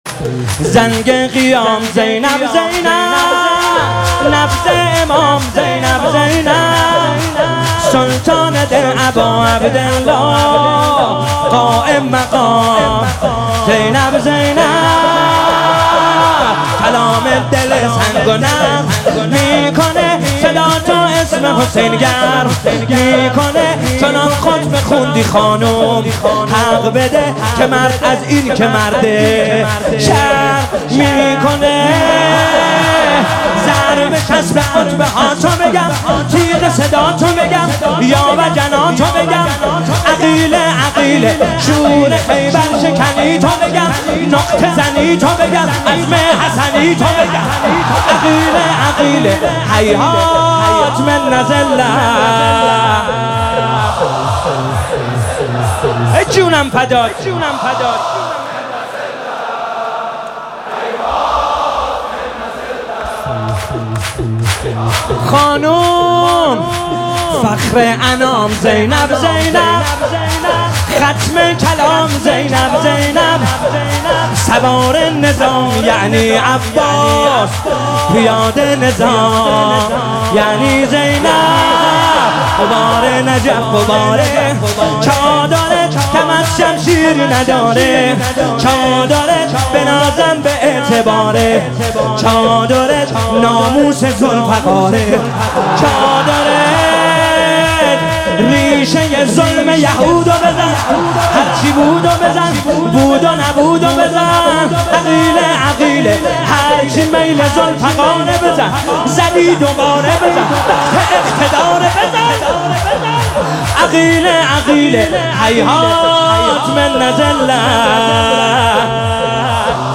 محفل عزاداری شب پنجم محرم